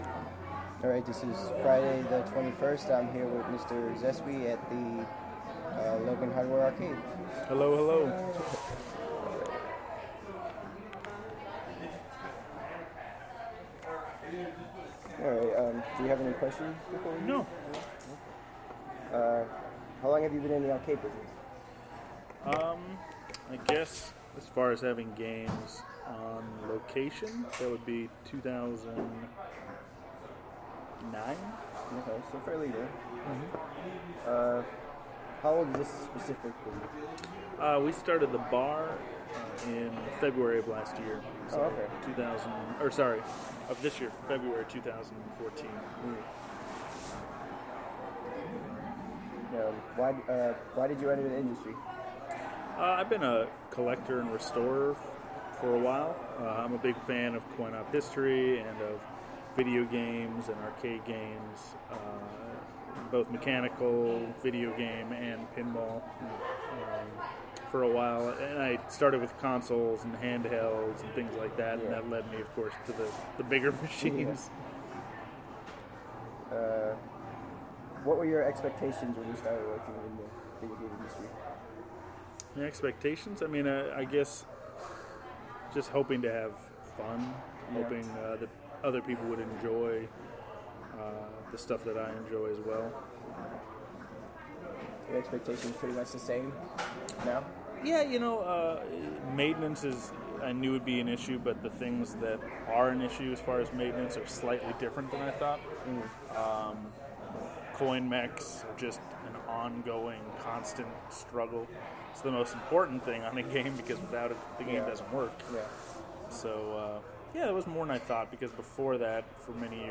raw interview